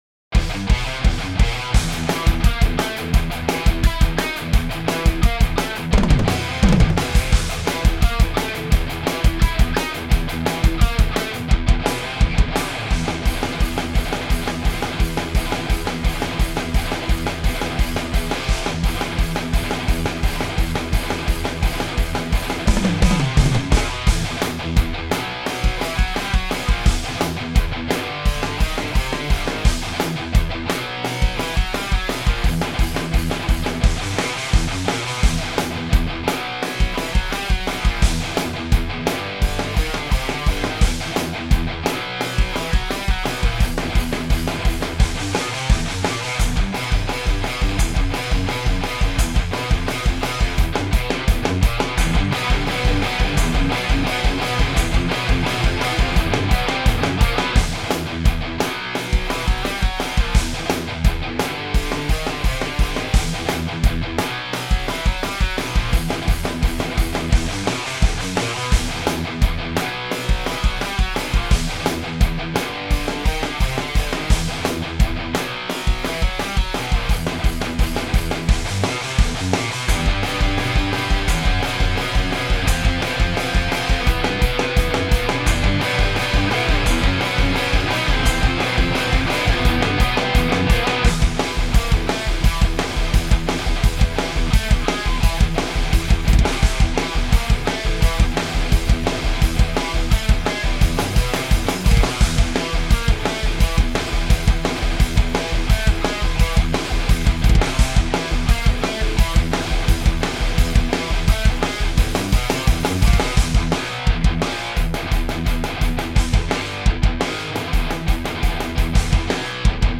New Demo Thrash Tune: Saddle up!
Here's the rhythm demo with a few rough patches yet to clean up.
Drums are GGD Invasion Nastier than Thou with a few tweaks
Probably my longest fast tune ever.
It feels very throwback classical, while still being very :rawk: .
Riffs snaking all over the place!
Not to mention the complete lack of bass!
killer riffs man, sounds great!!